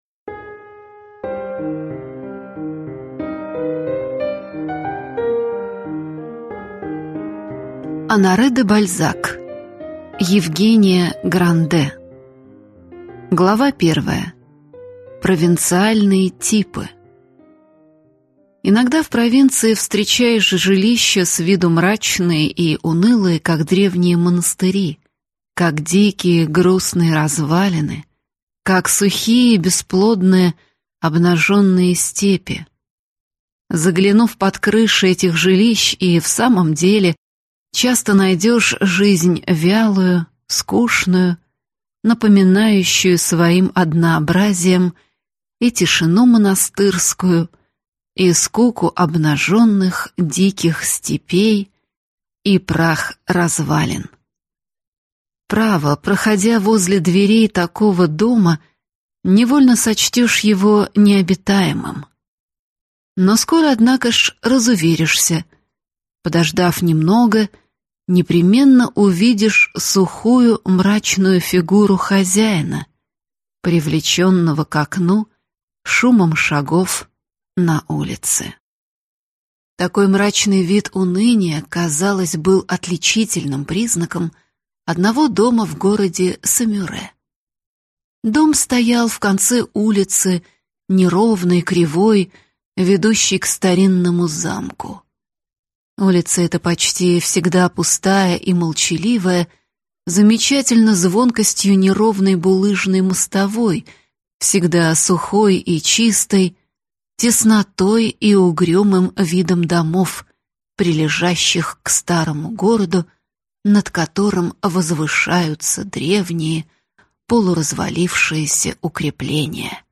Аудиокнига Евгения Гранде | Библиотека аудиокниг